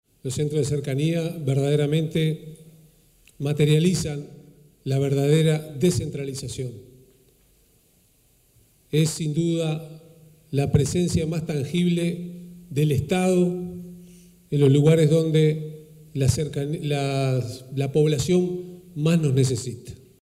El Gobierno de Canelones y el Correo Uruguayo realizaron la inauguración del Centro de Cercanía de 18 de Mayo, ubicado en la calle Av. Maestro Julio Castro esquina Solís.